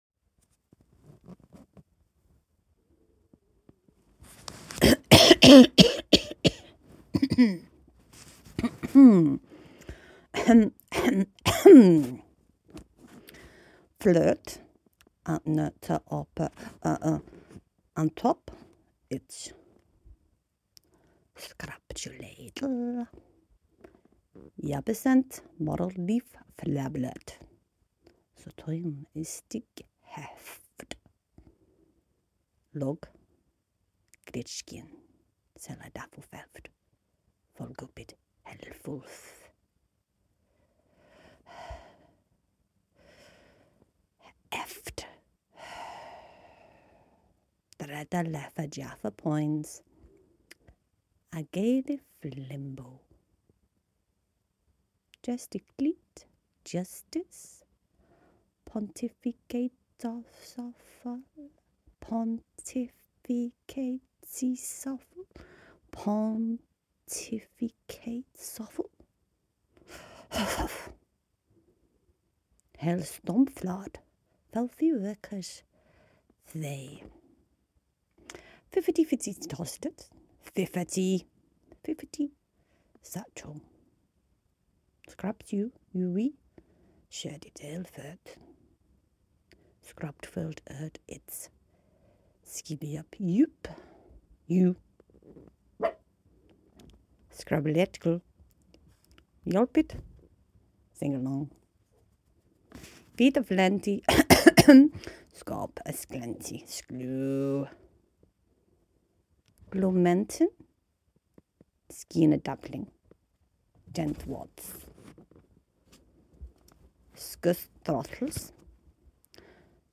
Even the dog objected to these vocals (as you'll hear mid way through).